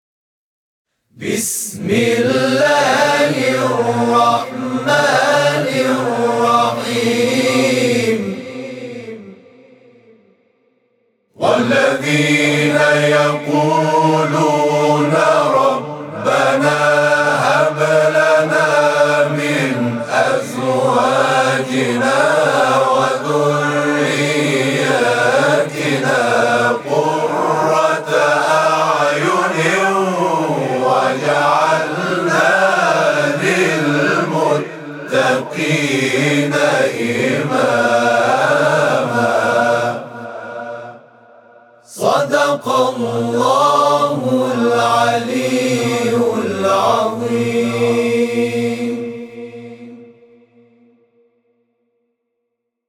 صوت همخوانی آیه 74 سوره فرقان از سوی گروه تواشیح «محمد رسول‌الله(ص)»